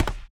footsteps
stepstone_6.wav